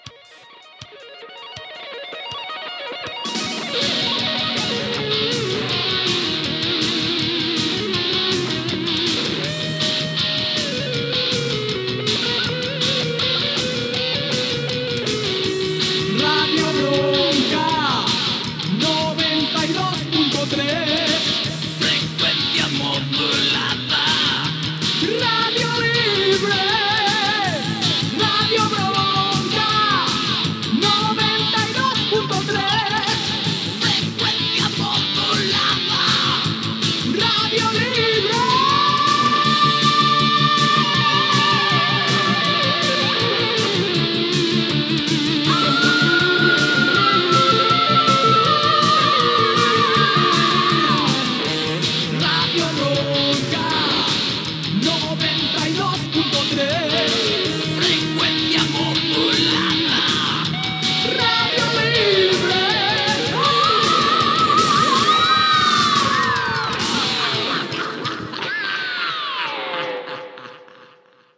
Cançó identificativa de la ràdio